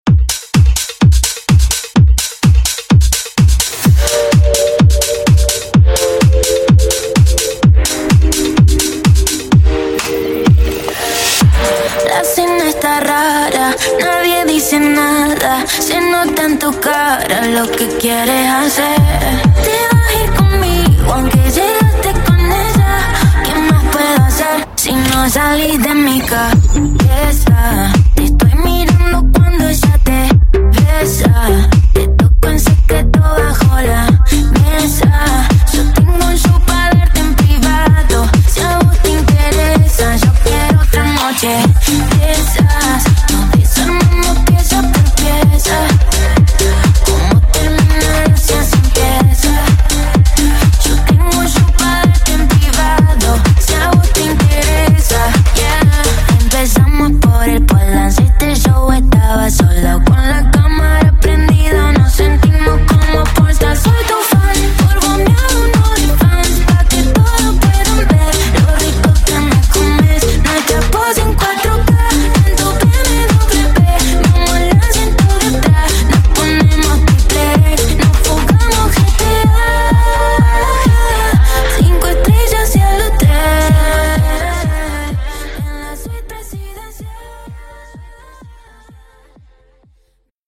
Genre: DANCE
Clean BPM: 118 Time